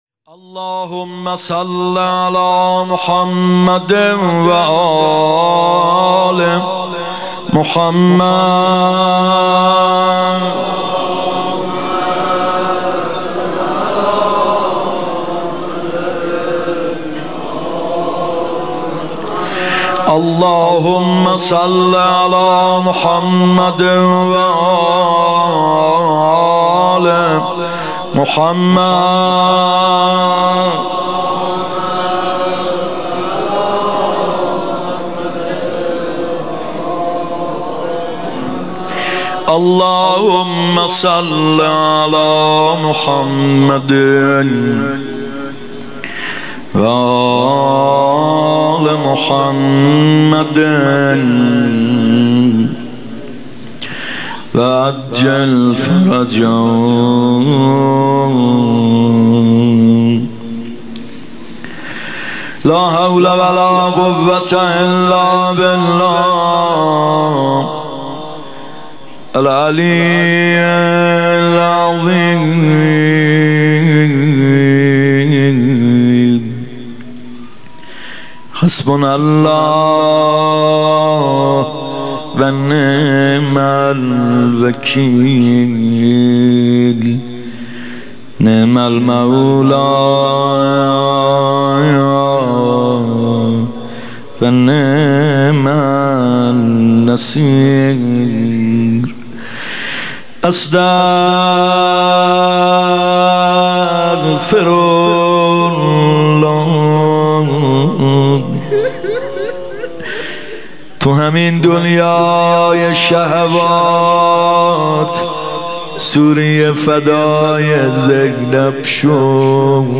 شهادت امام جواد 96 (هیات یامهدی عج)
مناجات